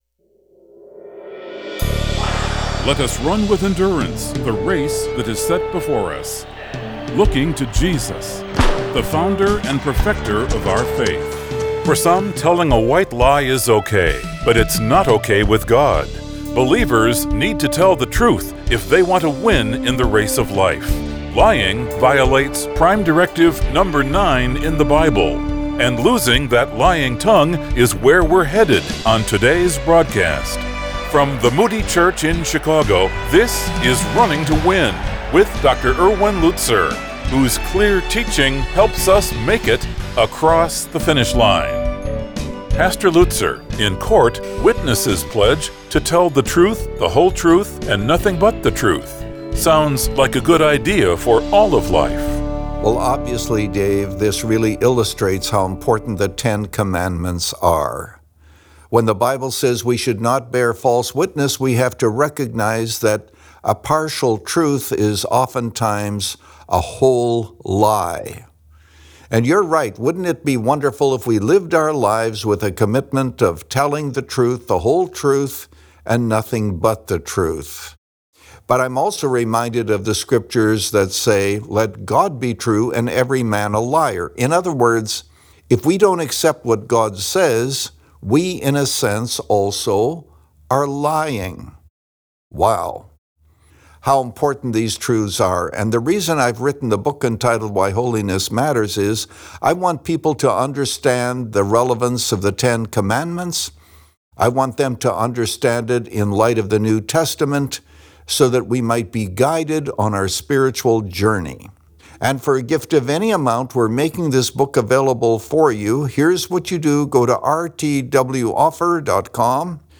Since 2011, this 25-minute program has provided a Godward focus and features listeners’ questions.